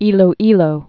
(ēlō-ēlō)